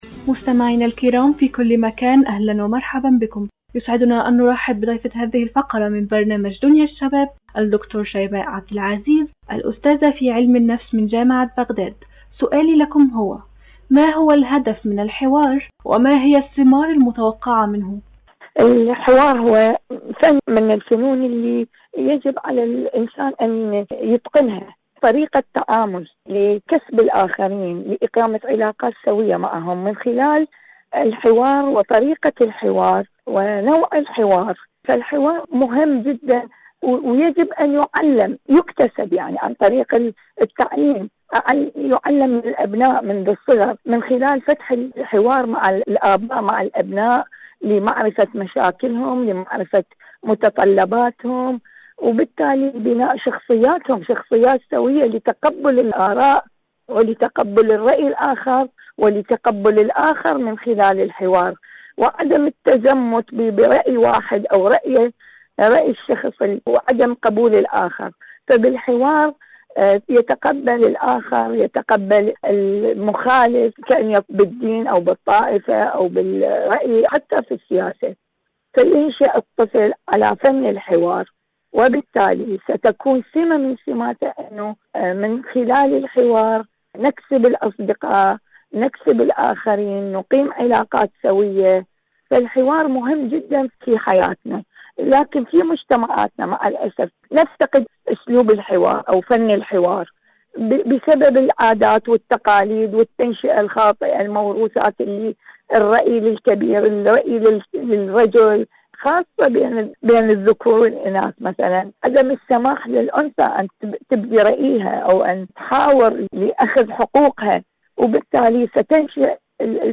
مقابلة إذاعية